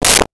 Whip 02
Whip 02.wav